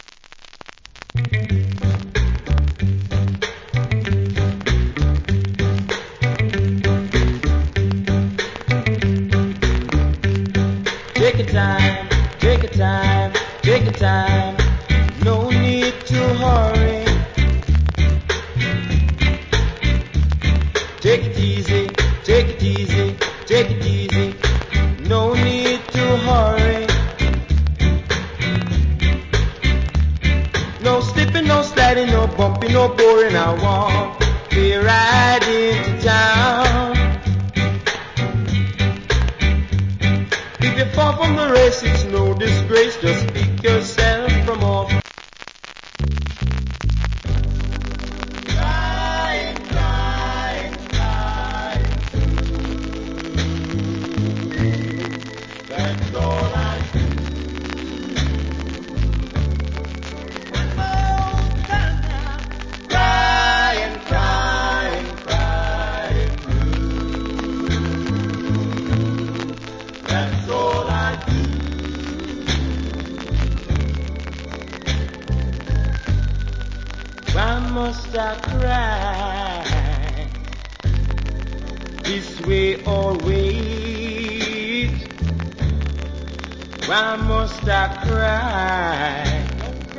Old Hits Rock Steady.